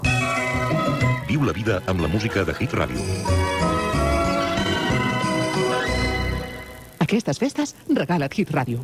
Indicatiu nadalenc de l'emissora